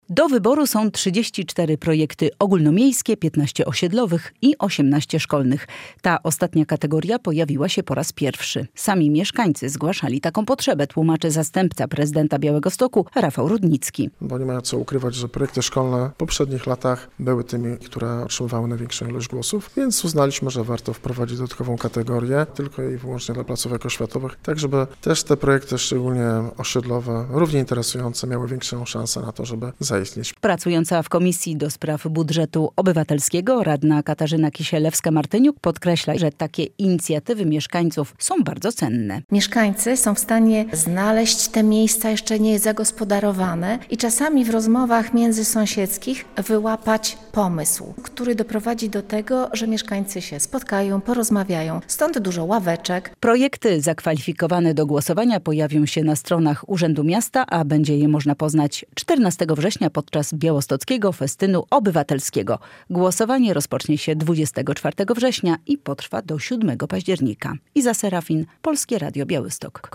Wkrótce głosowanie na budżet obywatelski - relacja
Mieszkańcy są w stanie znaleźć te miejsca jeszcze niezagospodarowane i czasami w rozmowach międzysąsiedzkich wyłapać pomysł, który doprowadzi do tego, że mieszkańcy się spotkają, porozmawiają, stąd tak dużo ławeczek - mówi radna Katarzyna Kisielewska-Martyniuk.